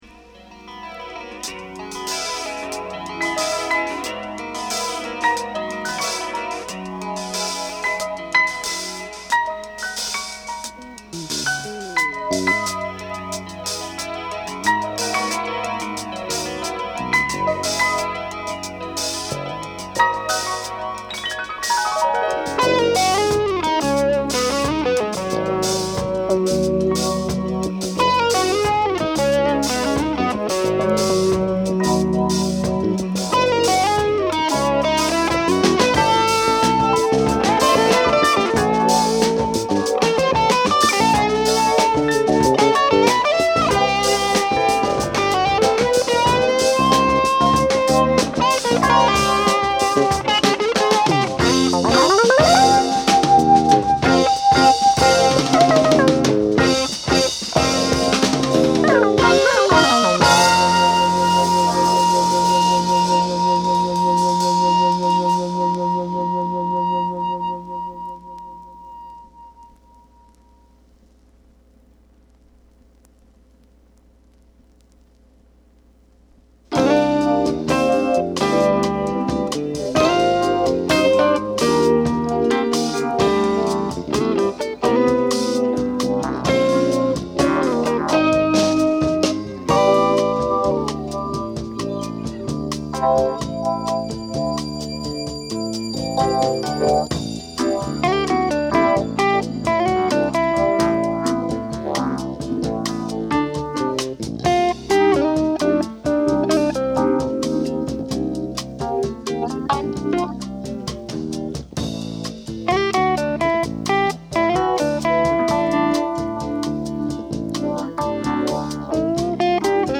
Genre: Jazz Fusion / Crossover